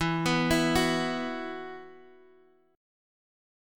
Esus2 chord